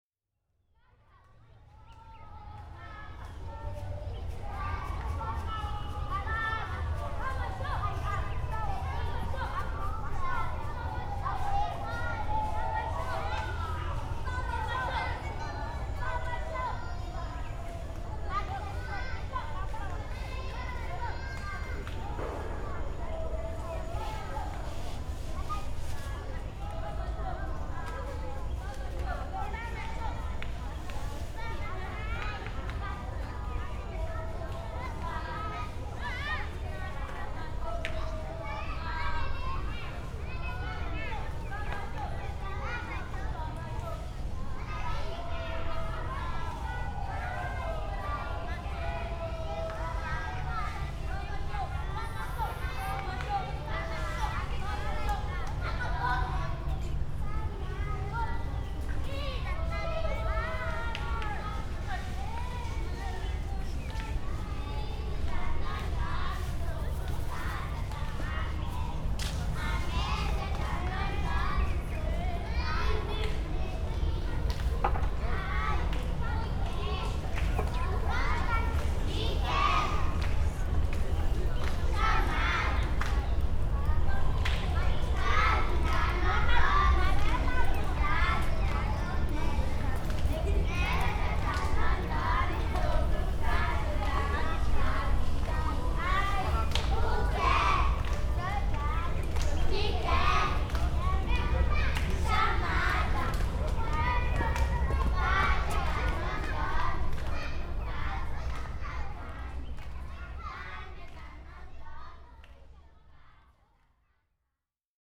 La République des enfants – 20D w1 – stéréo MS décodé L&R
Sous un kiosque à musique, très semblable à ceux du Jardin du Luxembourg, un groupe d’enfants chante et danse au rythme des claquements de mains. En cercle étroit, chacun passe à tour de rôle au centre, lance une apostrophe, les autres répondent en choeur, le soliste désigne alors le suivant qui prend sa place.